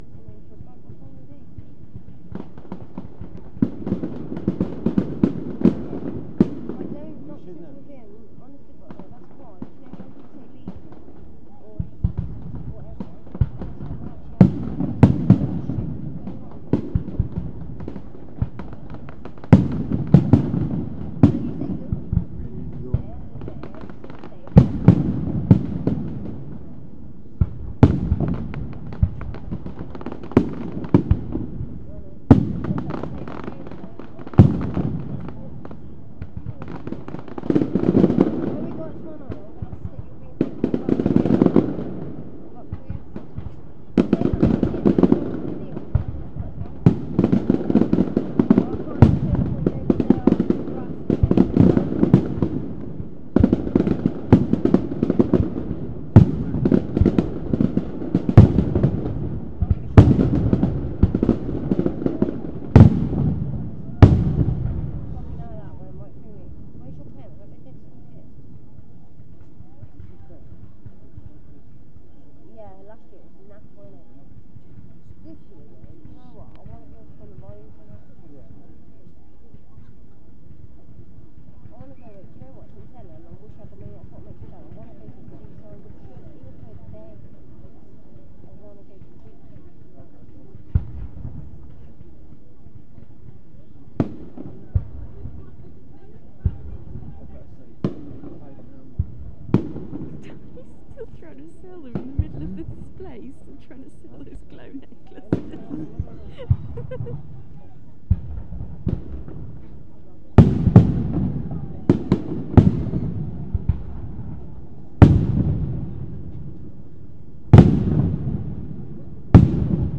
Festival Finale Fireworks 2
From Monday night's Worcester Festival 2012 Finale